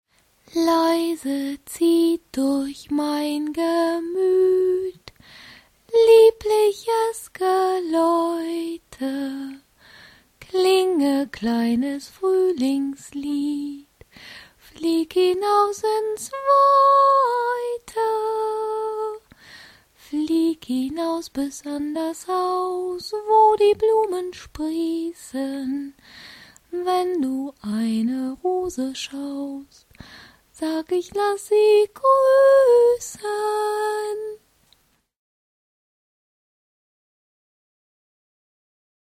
deutsche Sprecherin und Schauspielerin.
Sprechprobe: Sonstiges (Muttersprache):
german female voice over artist